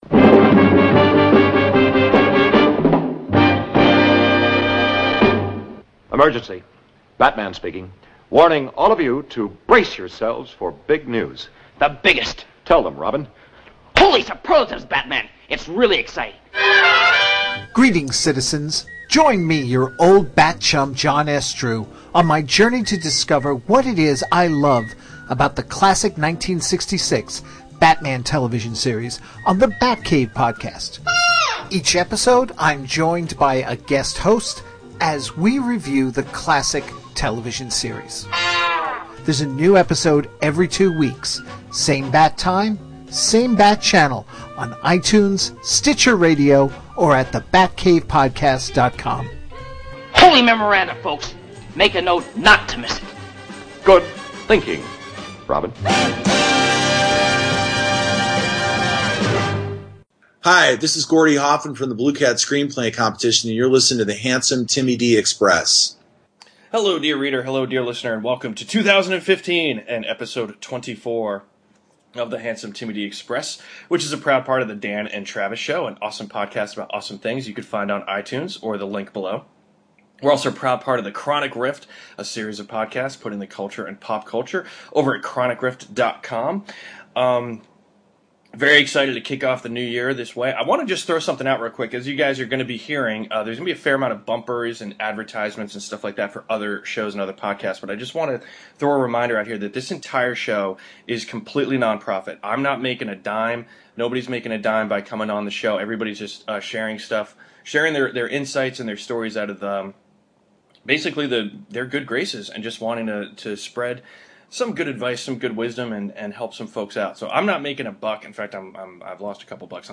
The Creative Soul: An Interview